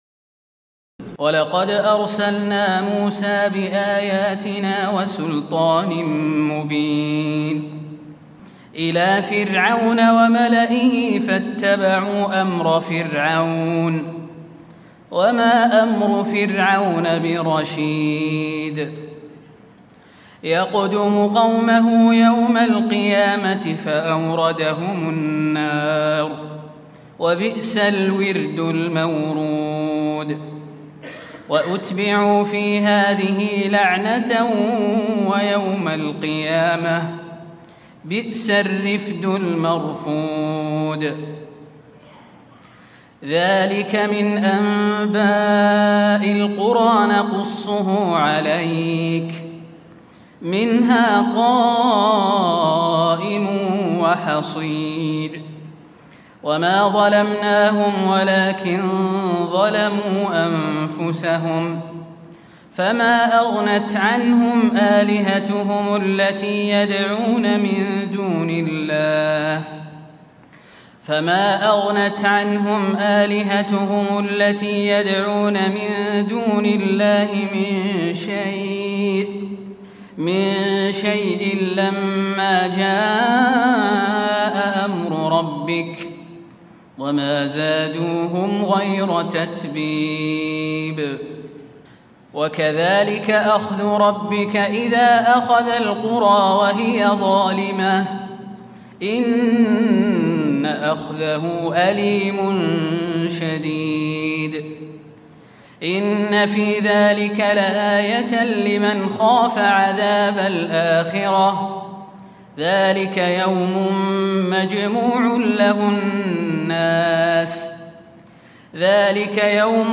تراويح الليلة الثانية عشر رمضان 1436هـ من سورتي هود (96-123) و يوسف (1-68) Taraweeh 12 st night Ramadan 1436H from Surah Hud and Yusuf > تراويح الحرم النبوي عام 1436 🕌 > التراويح - تلاوات الحرمين